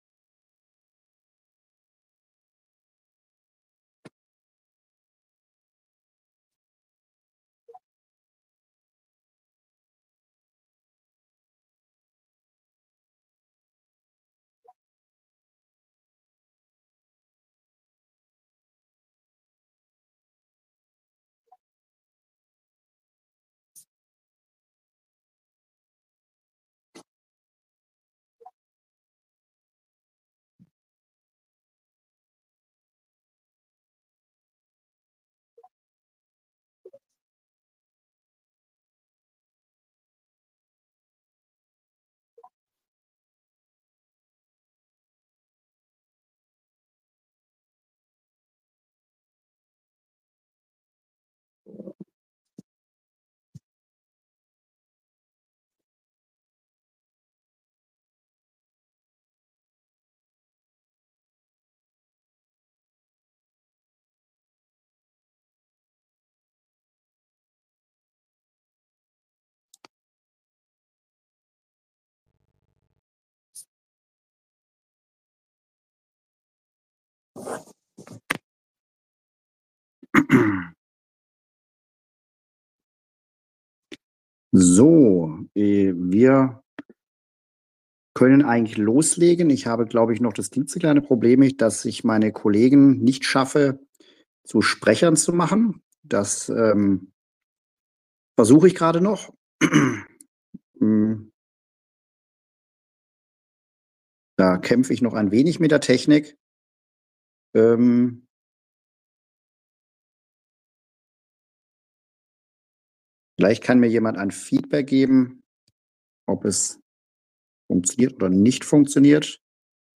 Telefonkonferenz zu vorläufigen Zahlen am 26. Februar 2026